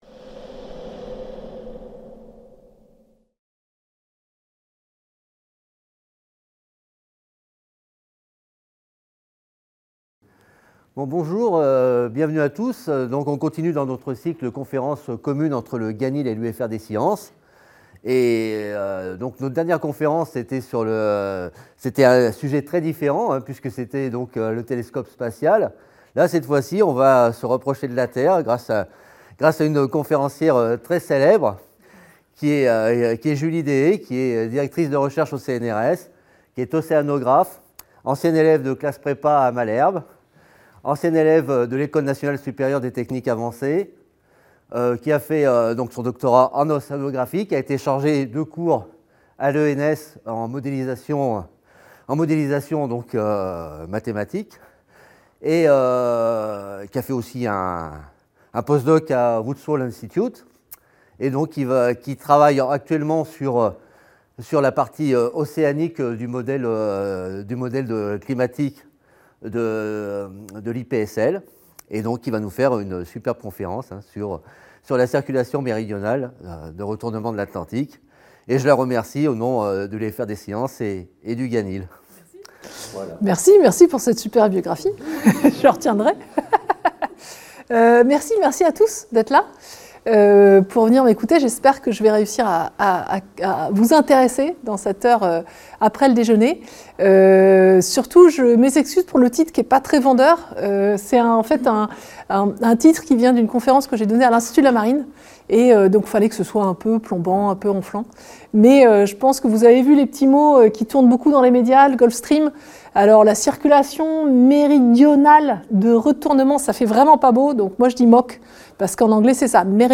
L’UFR des Sciences et le GANIL organisent une nouvelle conférence